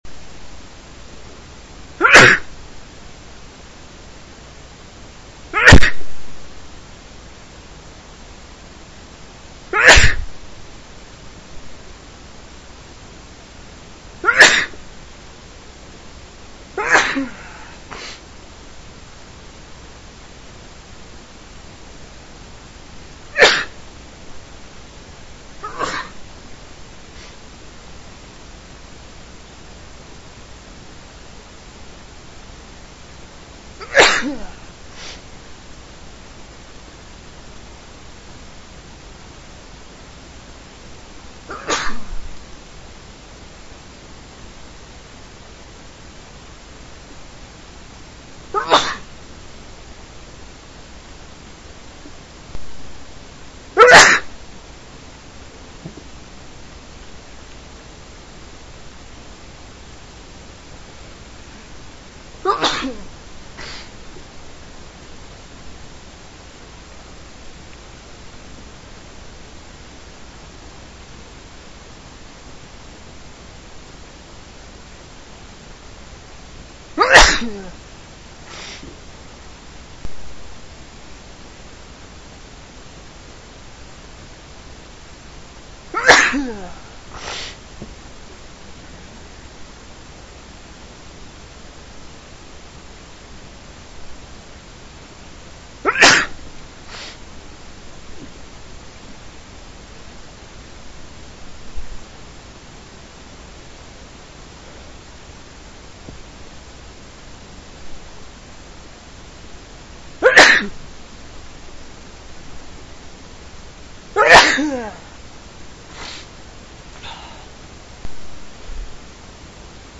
MALE WAVS